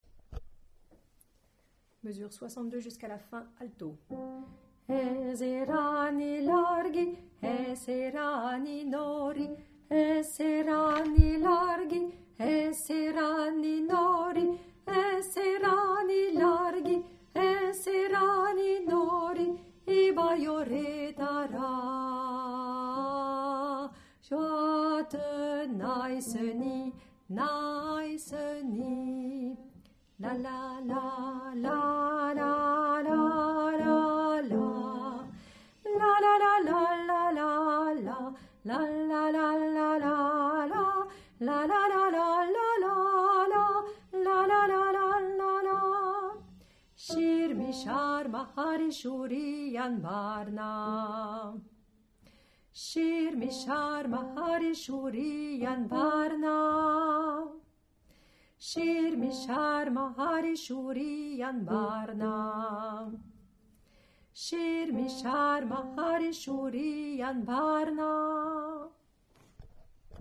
chant basque
Cela s'appelle « Xirmi, Xarma" et se prononce chirmi charma.
soprano
xirmi3_alto.mp3